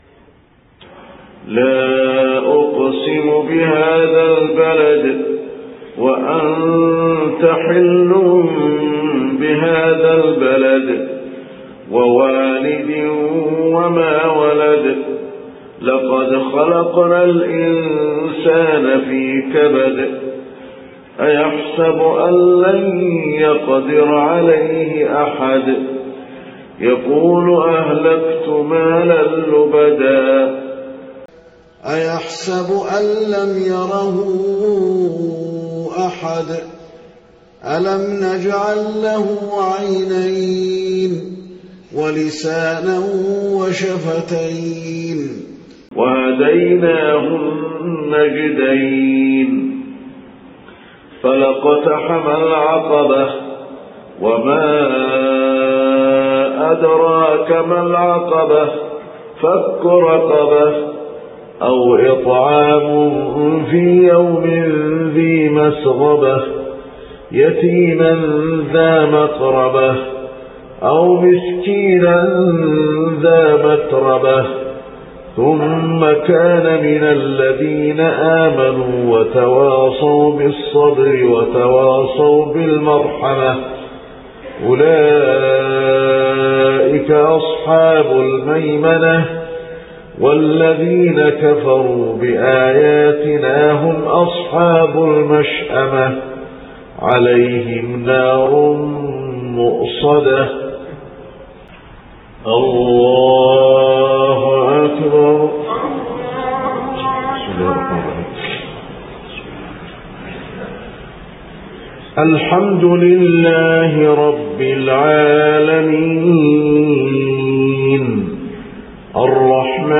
صلاة العشاء 1 صفر 1430هـ سورتي البلد والزلزلة > 1430 🕌 > الفروض - تلاوات الحرمين